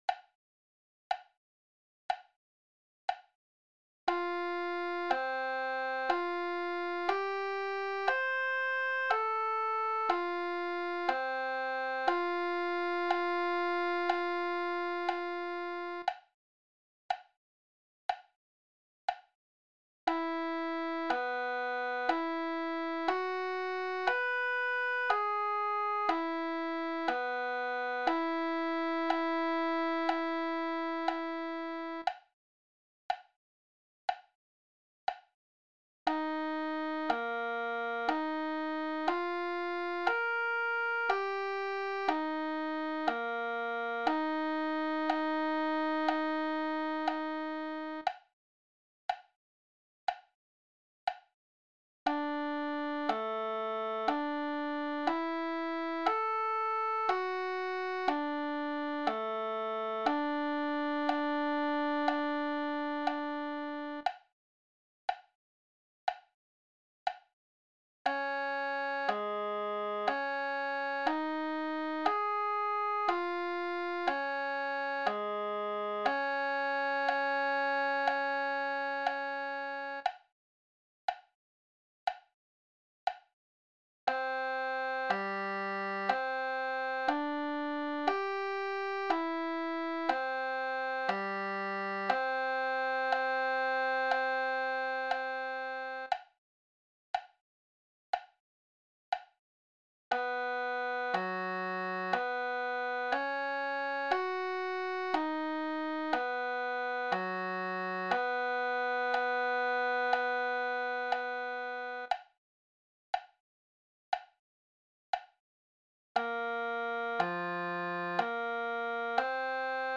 Play-Along Tracks - simple tracks to help with intonation and tone development
for B-flat trumpet